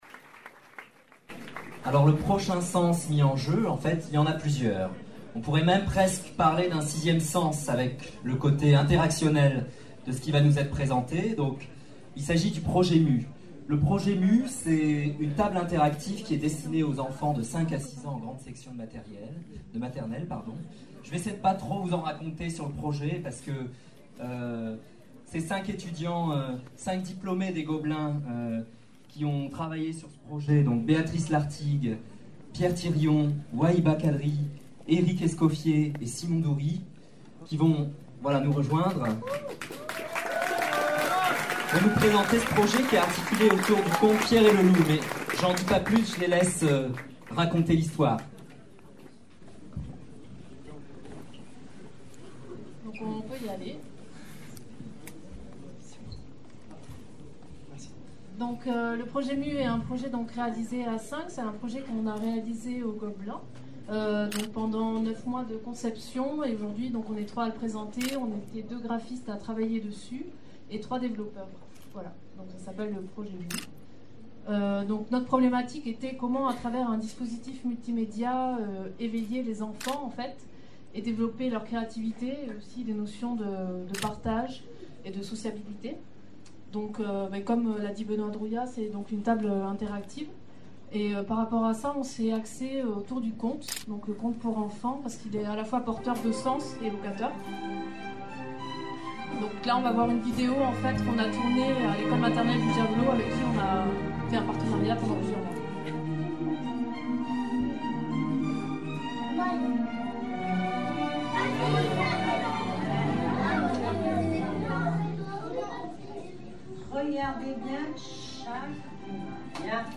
le 28 avril dernier lors du 8e vol. des Pecha Kucha de Paris.